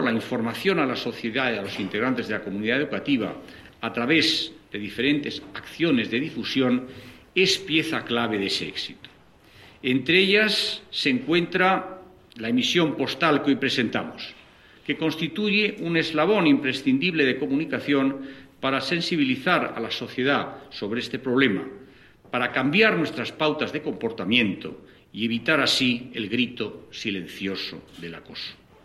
Declaraciones de Íñigo Méndez de Vigo Audio